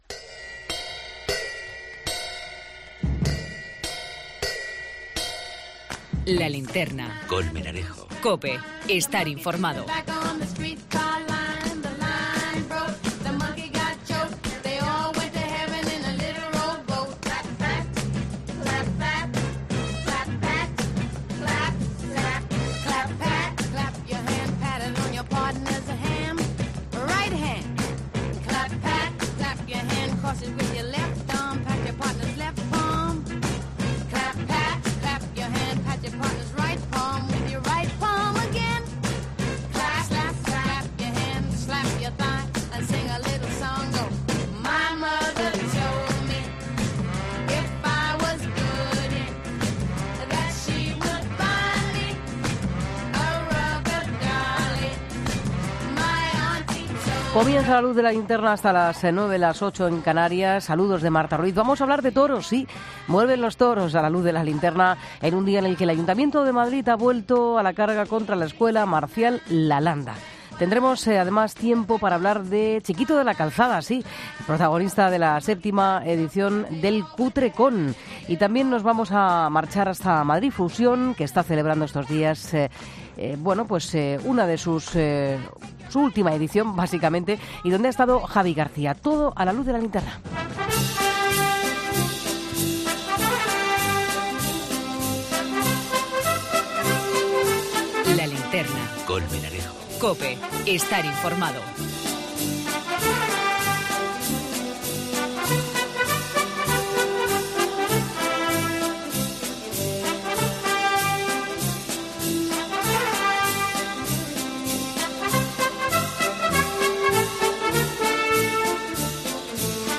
Hoy entrevista con Victorino Martín, ganadero y presidente de la Fundación El Toro de Lidia.